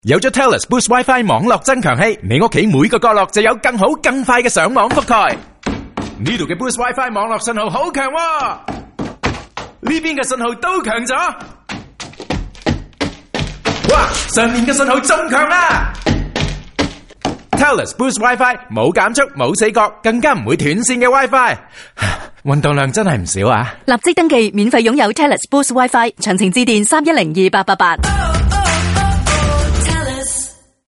To promote its Boost internet services, binaural radio ads in Cantonese, Mandarin, Hindi and Punjabi moved its narrator around the left and right stereo channels to make it sound like they were moving around the listener’s house, marveling at the signal strength.